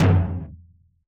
Mid Tom (New Magic Wand).wav